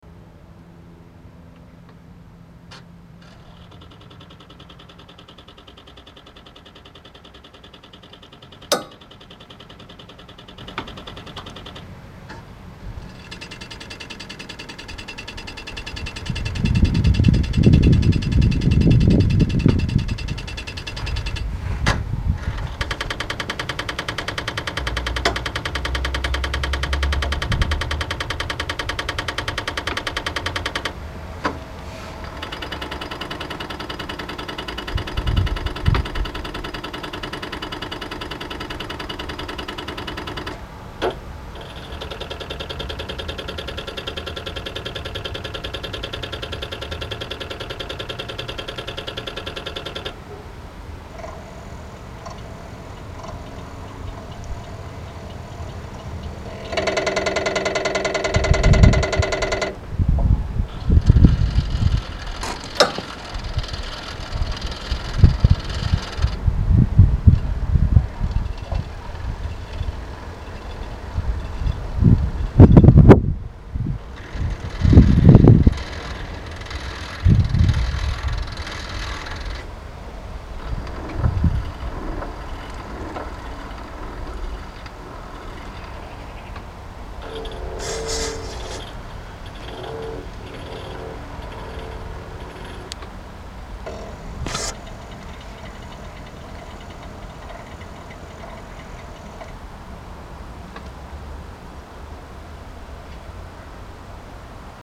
Il ne pleuvait pas aujourd'hui, j'en ai profité pour enregistrer le bruit de la séquence d'initialisation de mes détendeurs après coupure d'alimentation du groupe au disjoncteur.
La séquence commence par la recherche des 6 butées (on entend les tac-tac-tac de forçage en butée) et ensuite 6 ouvertures (rrr-rrr-rrr-rrr).
Par contre, j'ai des détendeurs qui ne font pas le même bruit, je ne sais pas si c'est normal.
1) Les 5 électrovannes-UI butent l'une après l'autre.
2) L'électrovanne-générale (?) : mouvement puis butée très bruyante puis mouvement
3) Les 5 électrovannes-UI sont mis en mouvement l'une après l'autre : bruit très discret de la 1ière et bruit irrégulier de la 4ième.
bruit-detendeurs-demarrage-clim.mp3